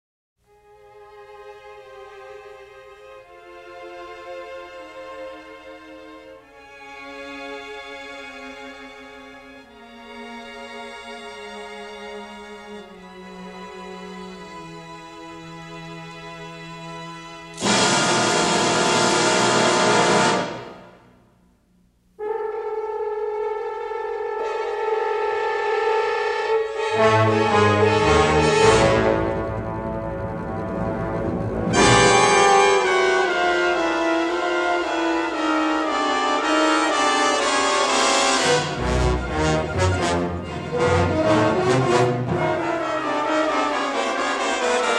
in their definitive stereo editions
romantic, full-blooded approach